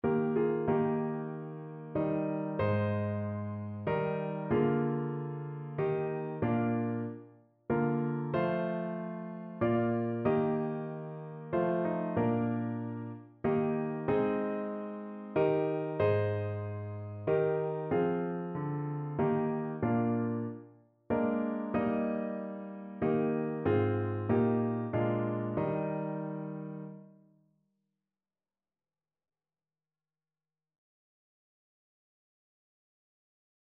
Notensatz 1 (4 Stimmen gemischt)
• gemischter Chor [MP3] 526 KB Download